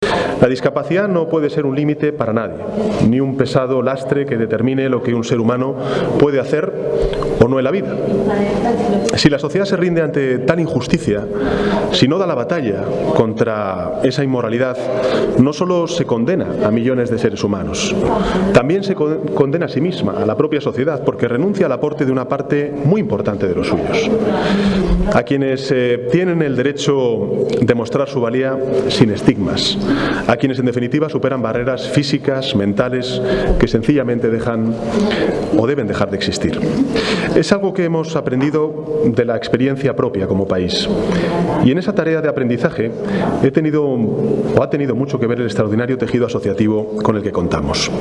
Arriba discurso del presidente, Pedro Sánchez.